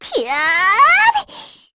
Lilia-Vox_Casting2_kr.wav